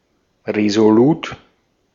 Ääntäminen
Vaihtoehtoiset kirjoitusmuodot (vanhentunut) determin'd Synonyymit dogged set steadfast strong resolved purposeful resolute strenuous unflinching Ääntäminen CA UK US UK : IPA : /dɪˈtɜː(ɹ)mɪnd/ GenAm: IPA : /dɪˈtɝmɪnd/